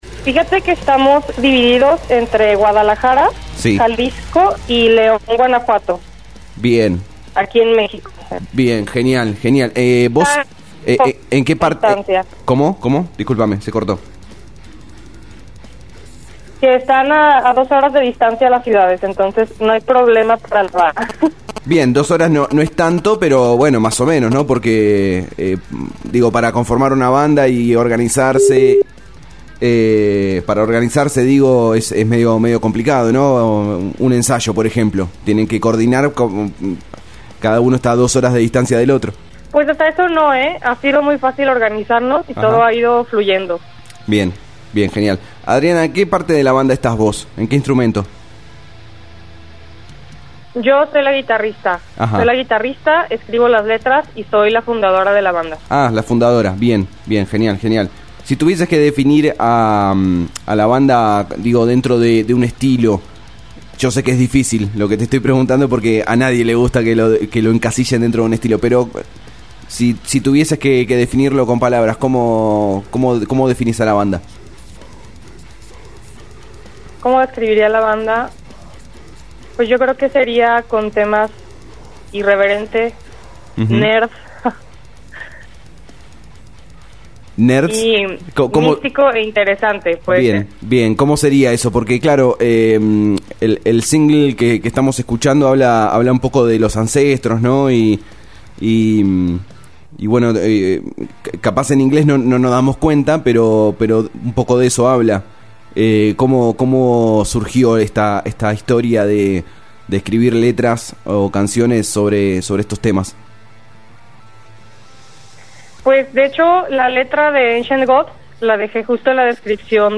Notas y Entrevistas realizadas en Om Radio